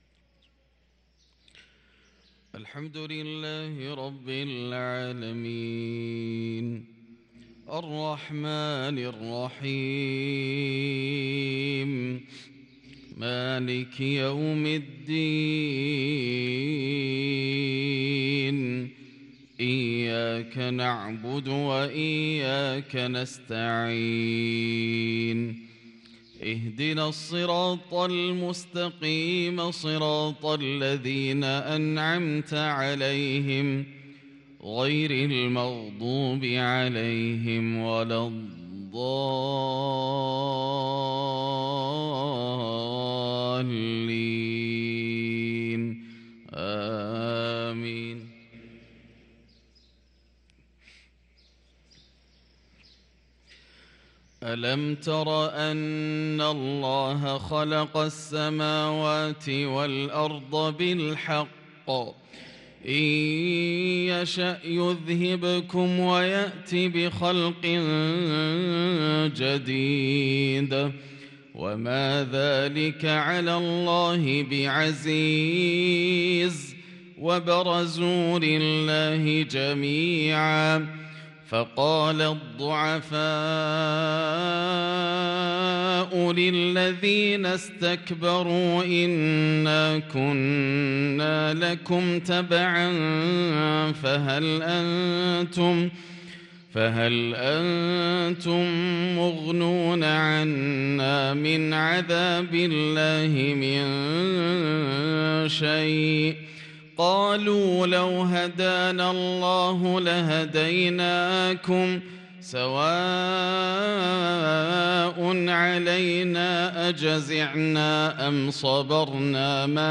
صلاة الفجر للقارئ ياسر الدوسري 3 جمادي الآخر 1444 هـ